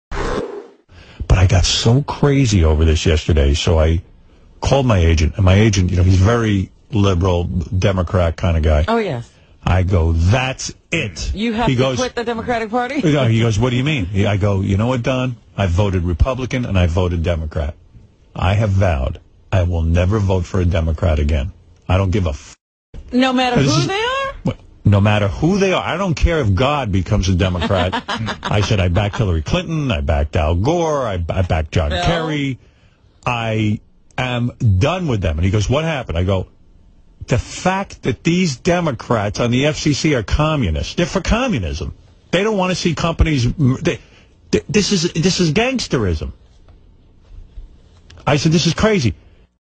Howard Stern made the remark on his syndicated radio program, according to an article by Media Research Center.
An audio sample of the broadcast was found on YouTube: